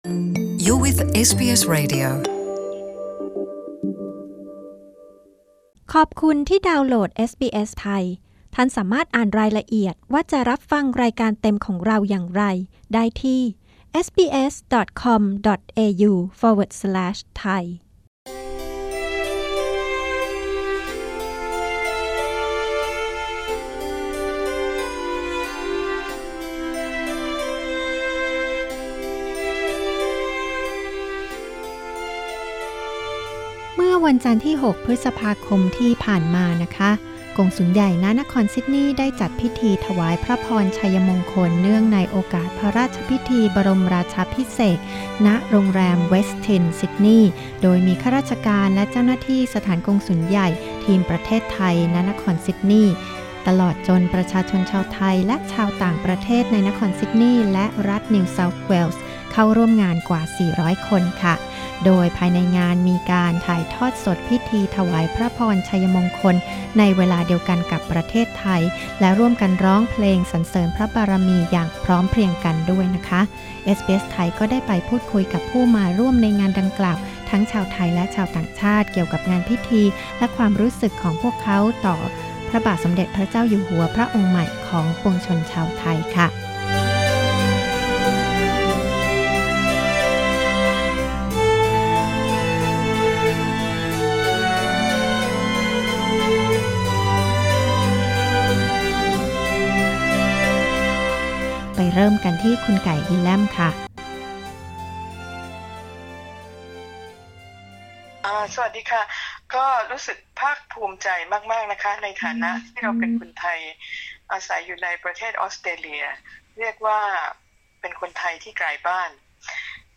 ฟังเสียงและชมภาพบรรยากาศ พิธีถวายพระพรชัยมงคล เนื่องในพระราชพิธีบรมราชาภิเษก ที่สถานกงสุลใหญ่ ณ นครซิดนีย์จัดขึ้นเมื่อ จันทร์ที่ 6 พ.ค. 2562